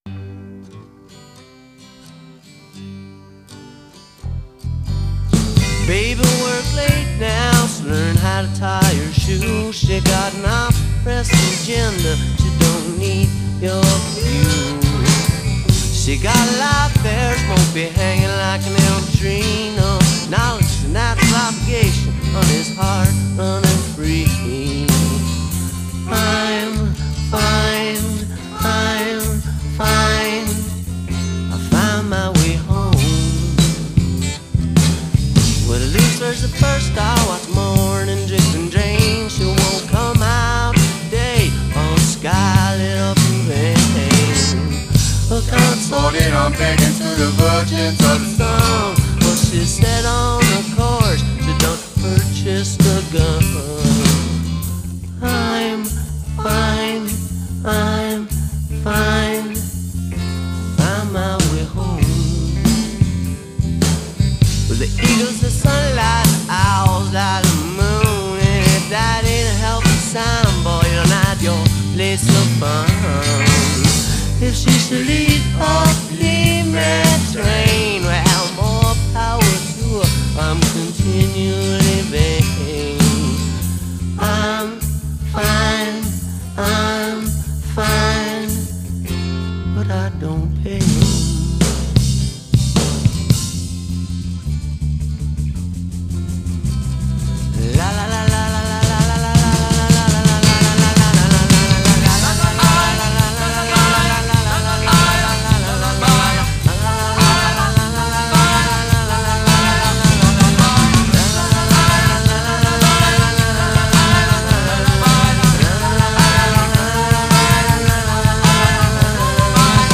Recorded September 1987 to March 1990
Guitar, Vocals
Keyboards, Bass, Vocals